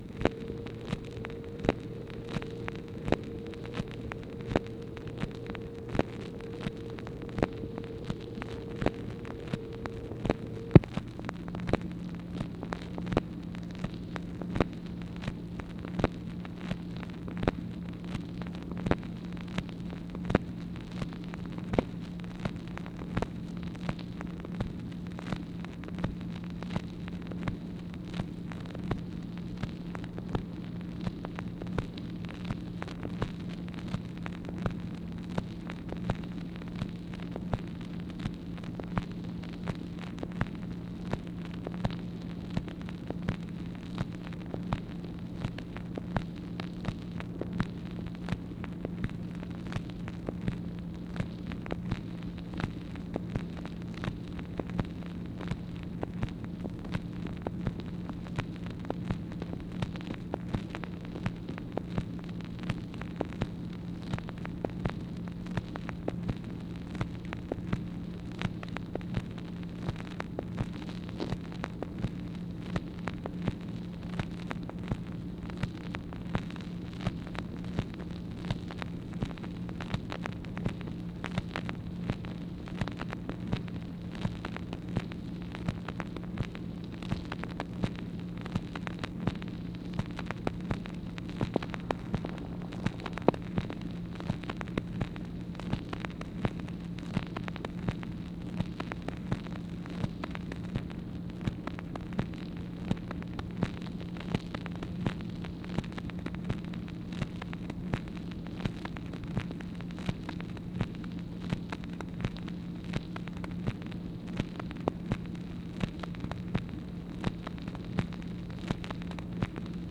MACHINE NOISE, April 30, 1964
Secret White House Tapes | Lyndon B. Johnson Presidency